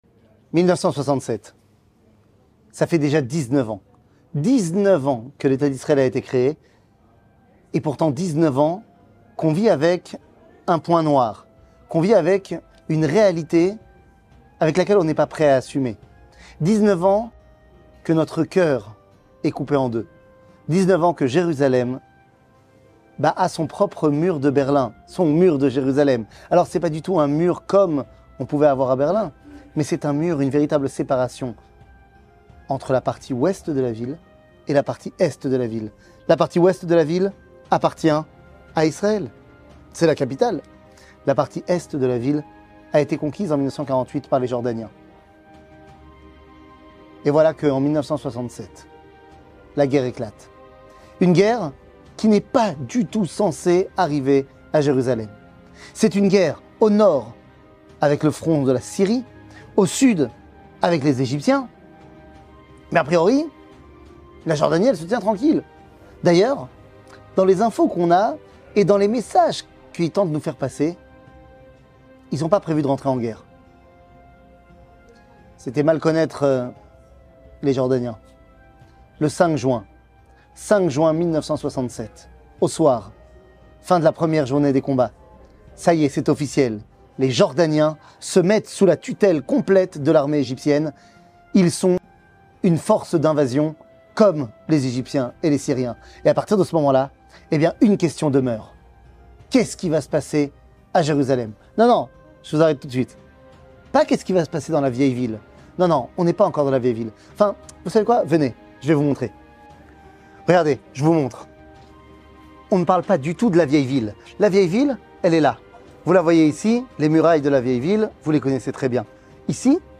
Partie 1 שיעור מ 19 יוני 2023 19MIN הורדה בקובץ אודיו MP3 (18.01 Mo) הורדה בקובץ וידאו MP4 (72.87 Mo) TAGS : שיעורים קצרים